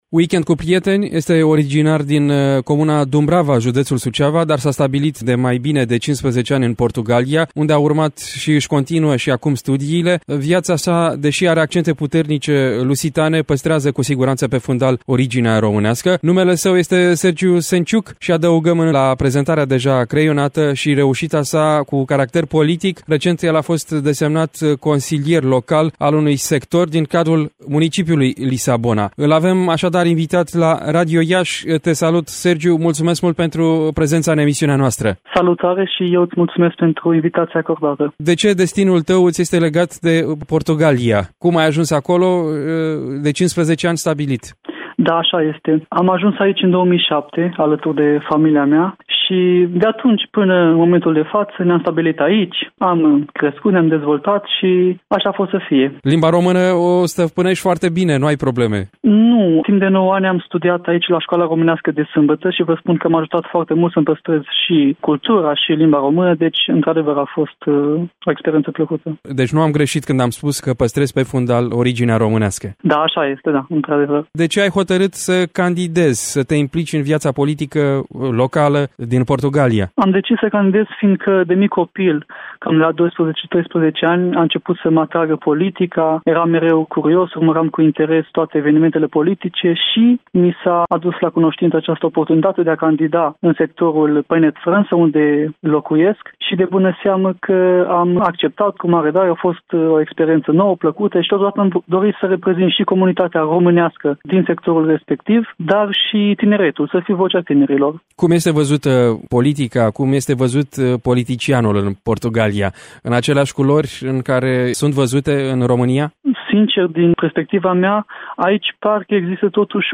Un interviu difuzat la emisiunea „Weekend cu prieteni”, ediția din 1 mai 2022.